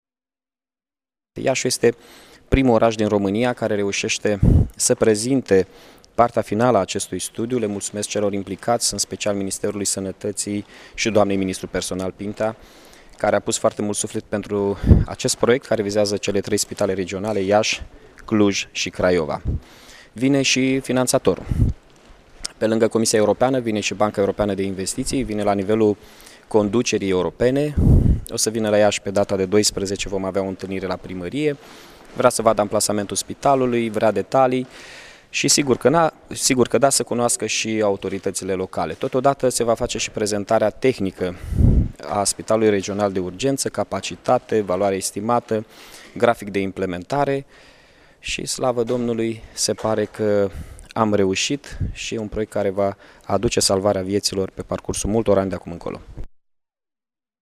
Declaratiile primarului municipiului Iasi, Mihai Chirica